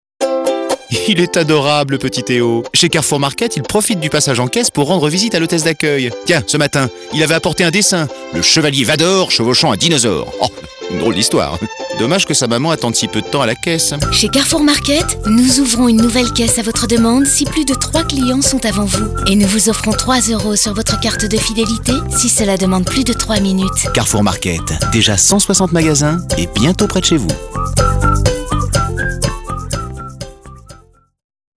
Défi relevé avec cette campagne radio écrite pour Carrefour Market, avec la voix d’Emmanuel Curtil (la voix française de Jim Carrey) et des portraits de clients dans lesquels on sent toute la bienveillance de l’enseigne et son engagement à leur rendre service.